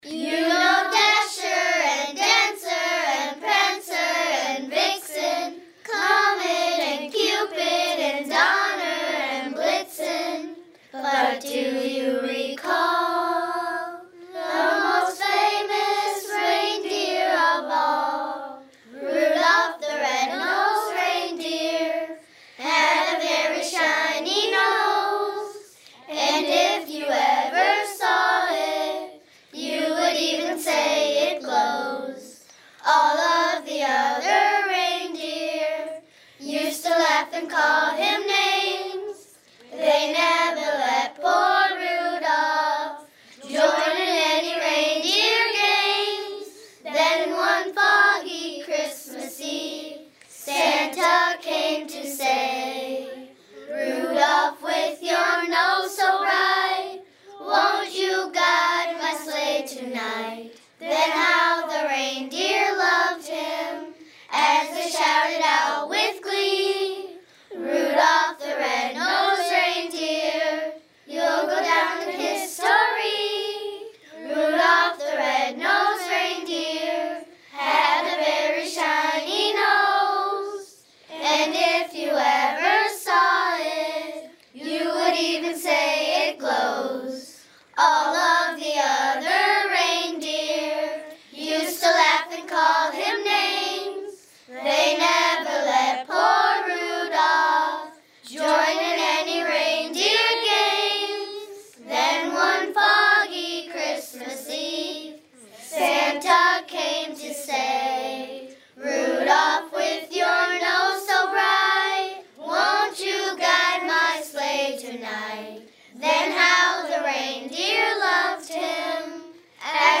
Sageville students will soon be featured on the radio singing their sure-to-be #1 hit for the holiday season…!